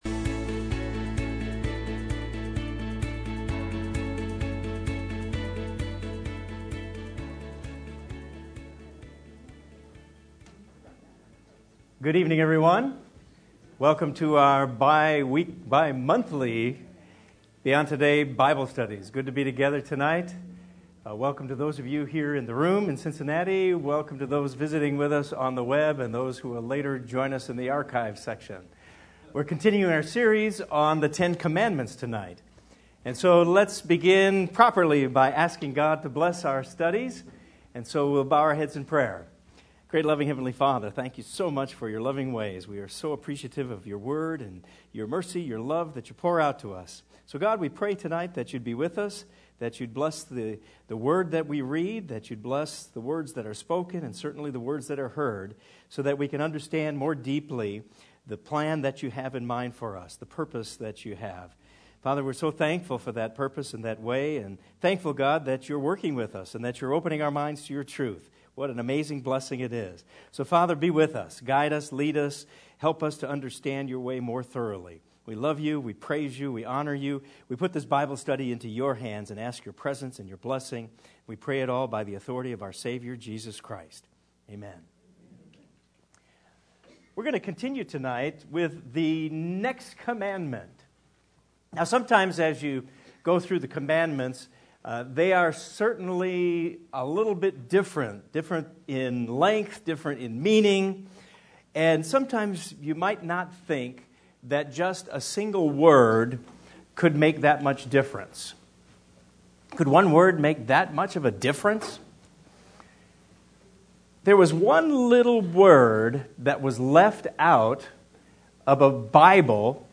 This is the seventh part in the Beyond Today Bible study series: The Ten Commandments.